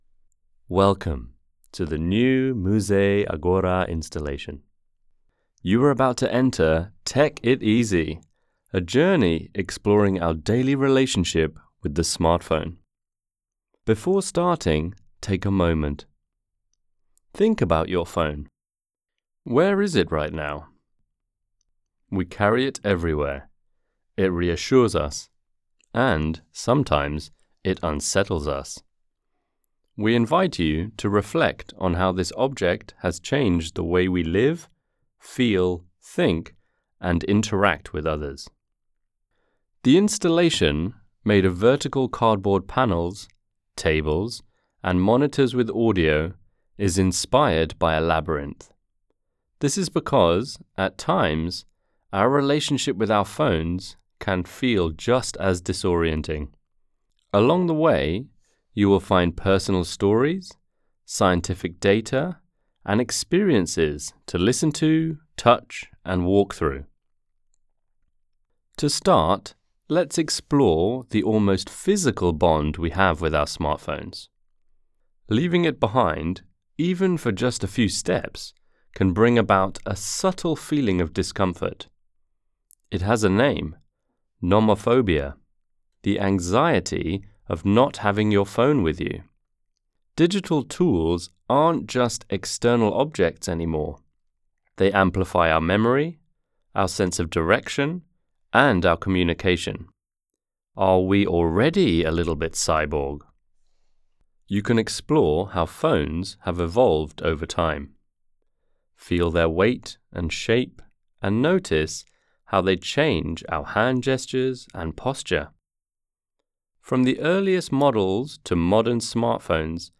Audio description Tech IT easy! - Living with your smartphone | Muse - Museo delle Scienze di Trento